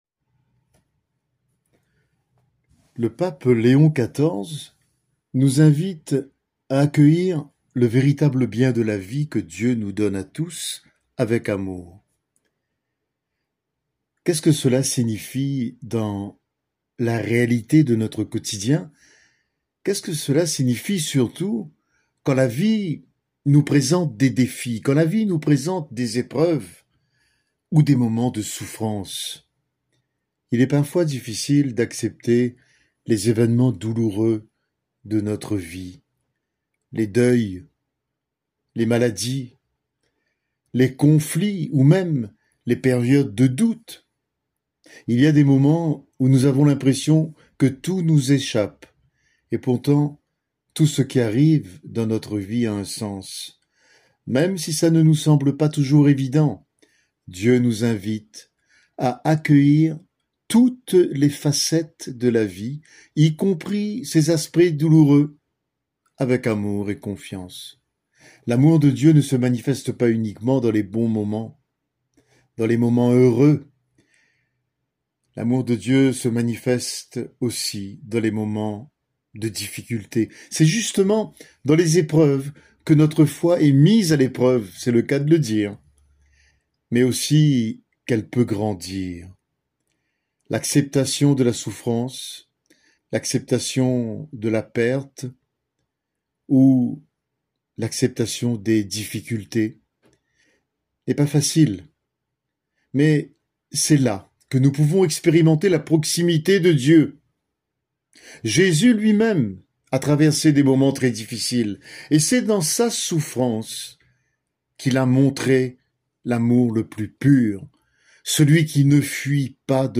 Prédication disponible en format audio.